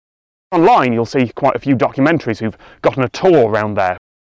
But examples aren’t hard to find, from young and not so young speakers:
But as we heard in the clips above, it’s possible to find Brits using gotten with a wide variety of meanings.